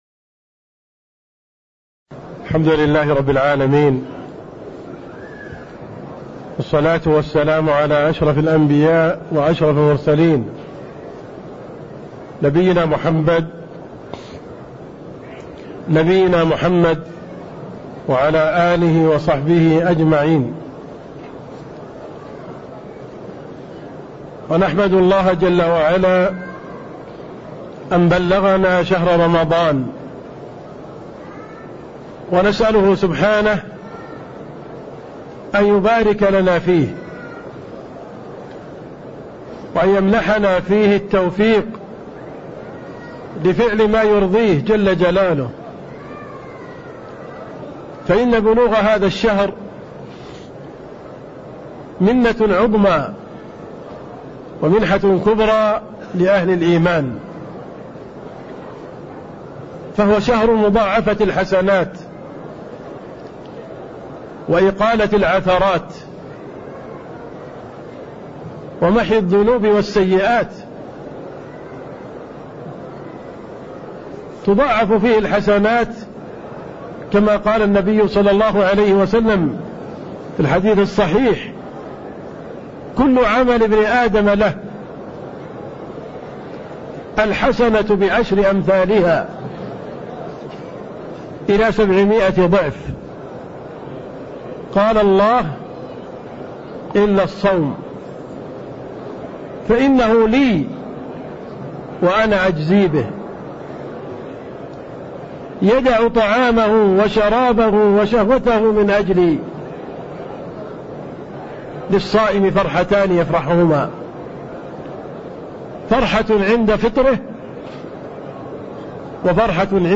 تاريخ النشر ٤ رمضان ١٤٣٥ هـ المكان: المسجد النبوي الشيخ: عبدالرحمن السند عبدالرحمن السند باب الحيض (13)